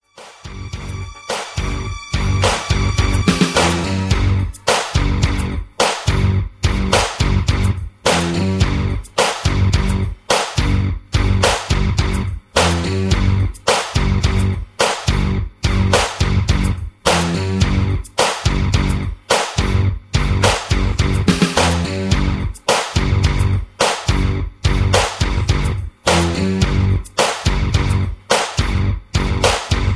(Version-1, Key-Ab) Karaoke MP3 Backing Tracks
Just Plain & Simply "GREAT MUSIC" (No Lyrics).